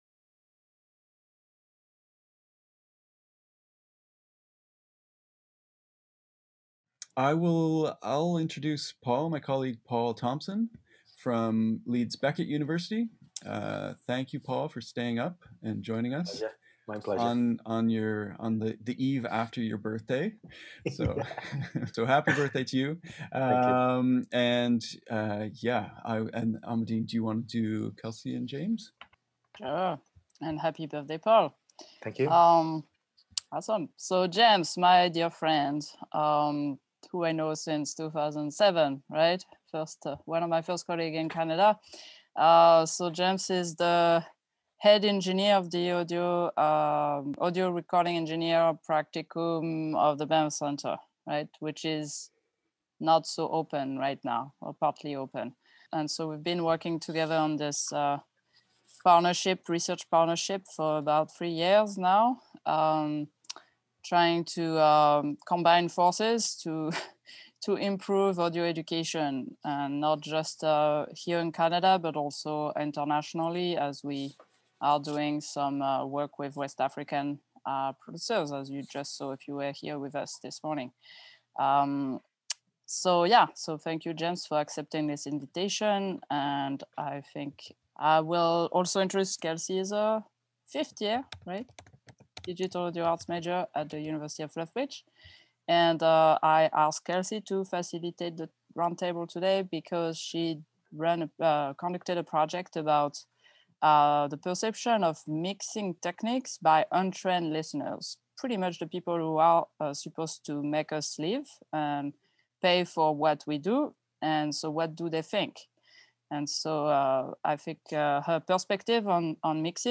Roundtable discussion about Critical Listening and Technical Ear Training | Canal U